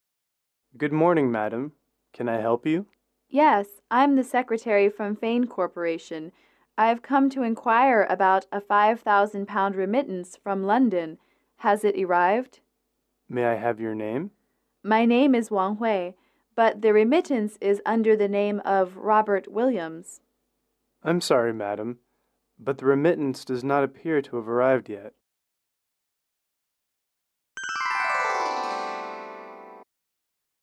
英语主题情景短对话47-3：汇款（MP3）